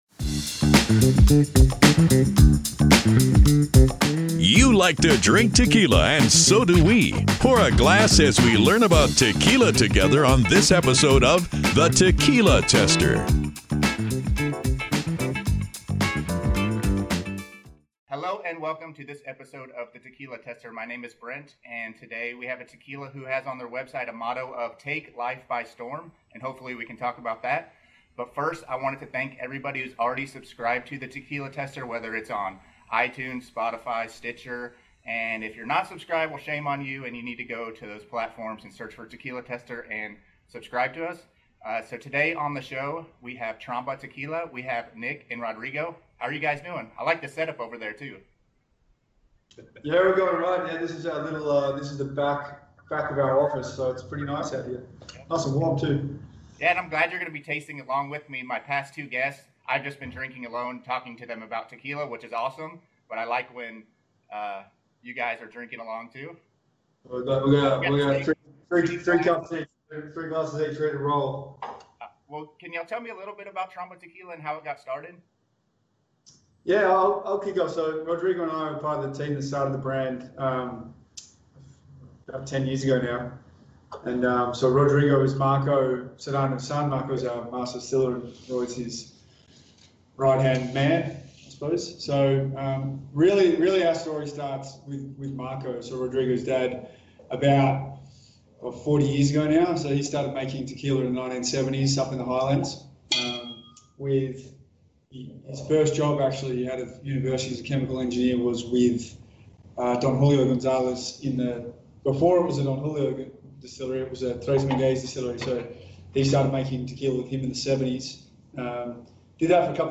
Entrevista Tequila Tester